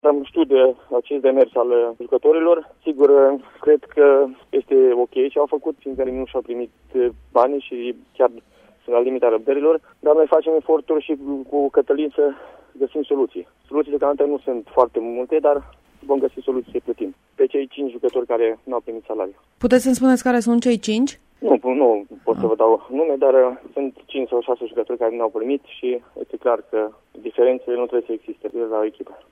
Despre situaţia din Valea Domanului, fostul internaţional Dorinel Munteanu, a declarat pentru Radio Reşiţa: